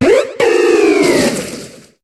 Cri d'Archéduc dans Pokémon HOME.